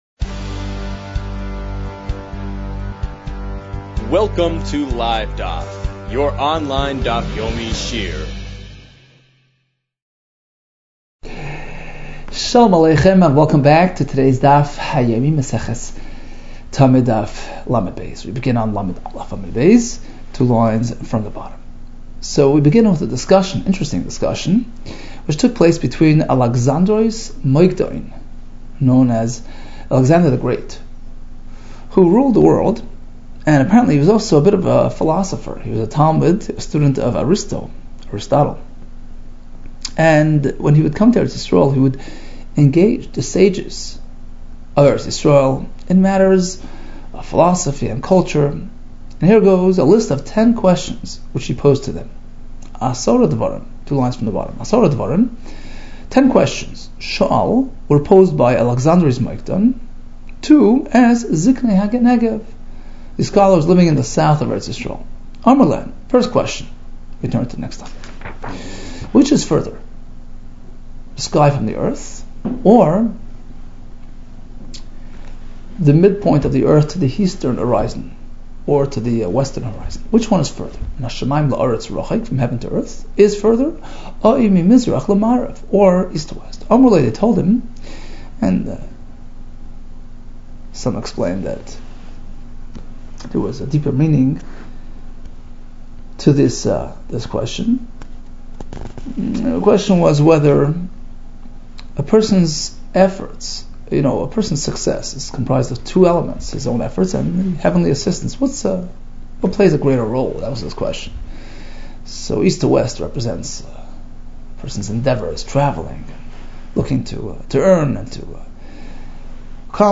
Tamid 31 - תמיד לא | Daf Yomi Online Shiur | Livedaf